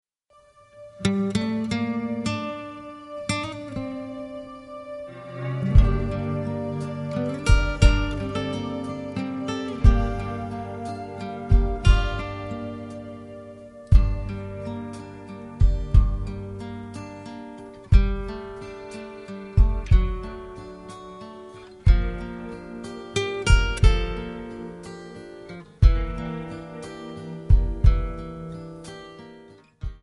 Buy Without Backing Vocals
Buy With Lead vocal (to learn the song).